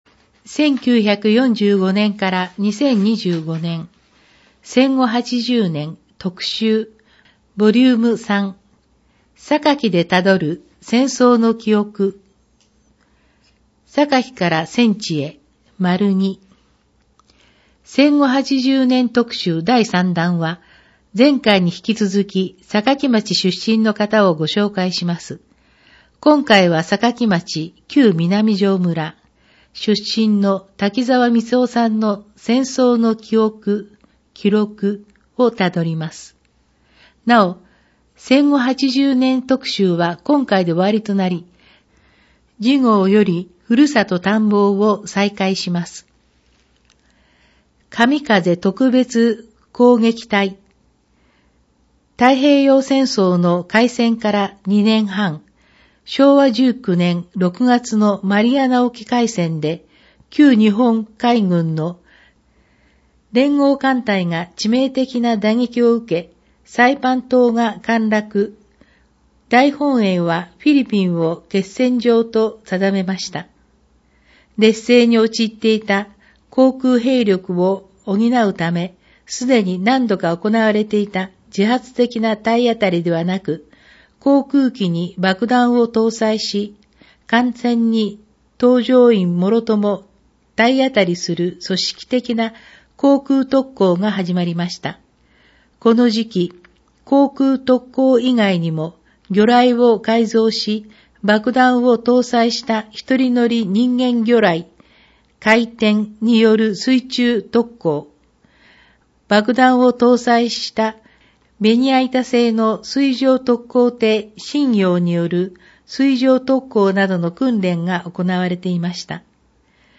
音訳版ダウンロード(制作：おとわの会）